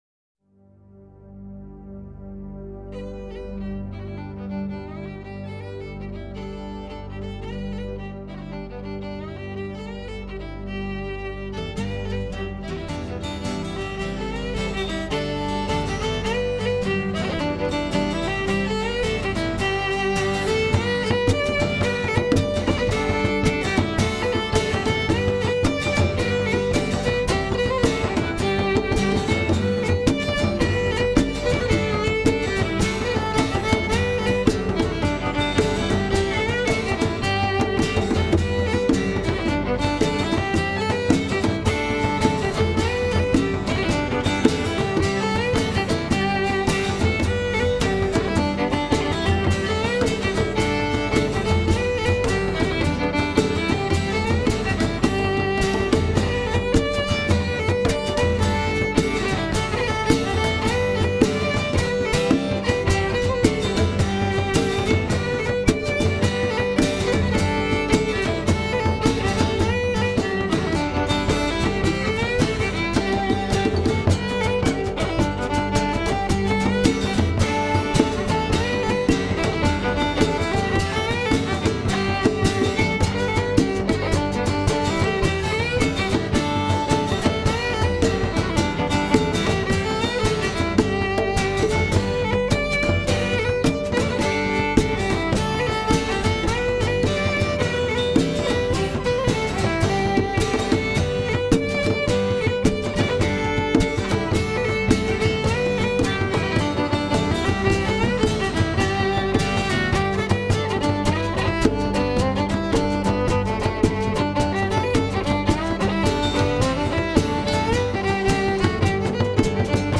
Fiddle, guitar; 12-string guitar, bass, keyboards; congas, percussion; bodhran, talking drum, oboe, vocals.
A 'live' recording of 'And Ryan Young's'.
A Ceilidh